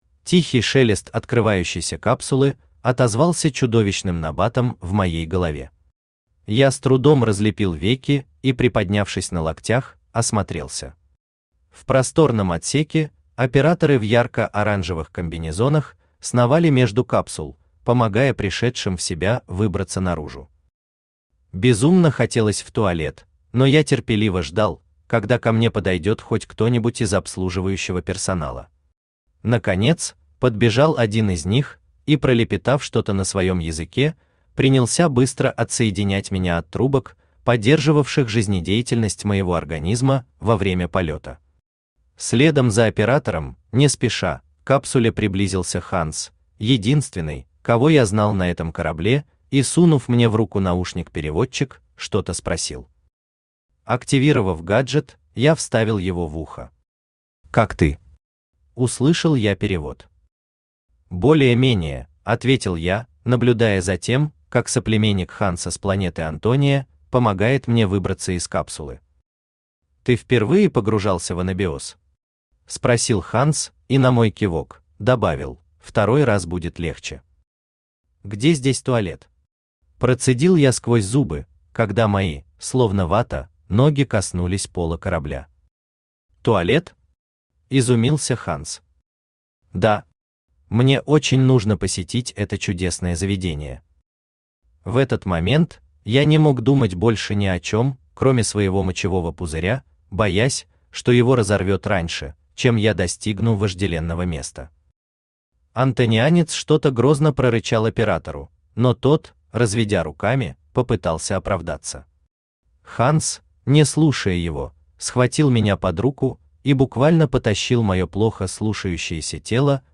Аудиокнига Наблюдатель | Библиотека аудиокниг
Aудиокнига Наблюдатель Автор Ник Алнек Читает аудиокнигу Авточтец ЛитРес.